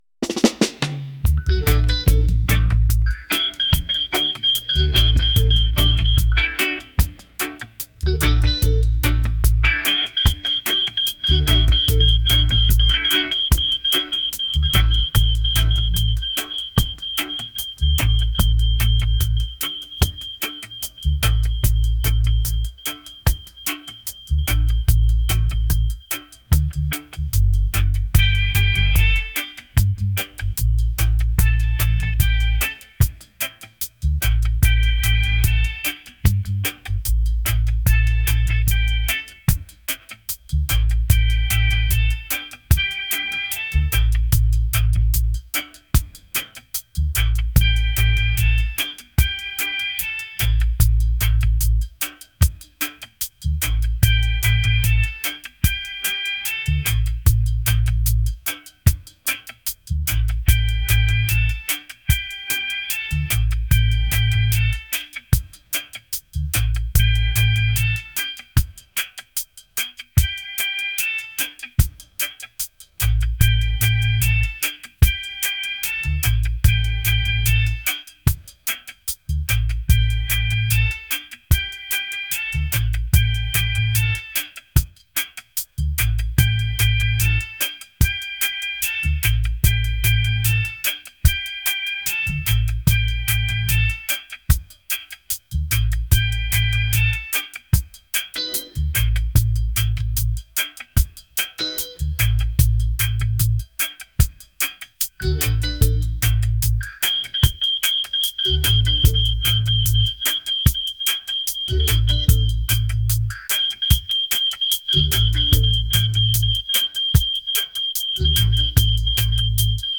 reggae | laid-back | soul